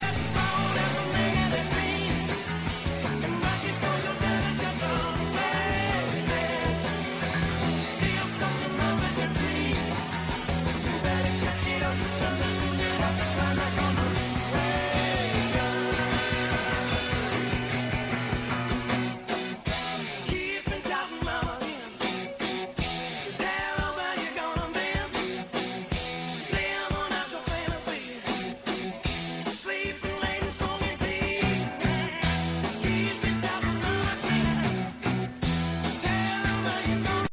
• The Original, Rockin' Tune From Boston.